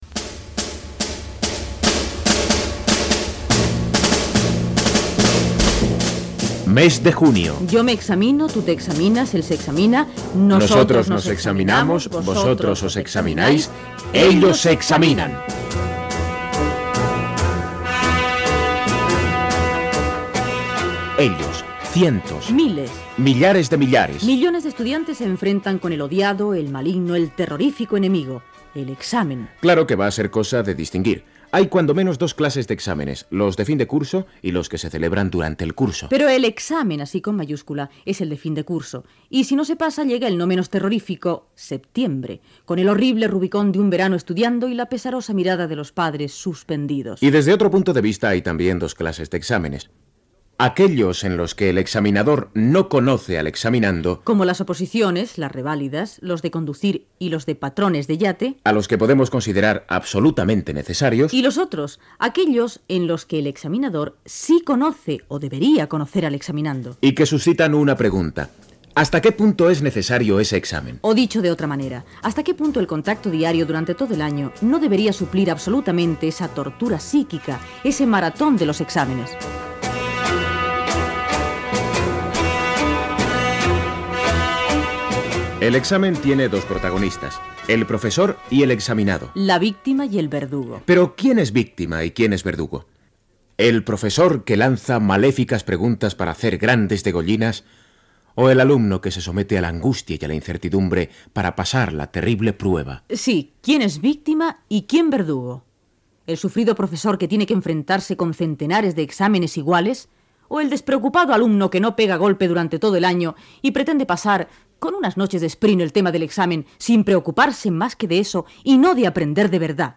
Els exàmens de final de curs amb l'opinió del professor universitari Manuel Alvar i d'alguns alumnes Gènere radiofònic Informatiu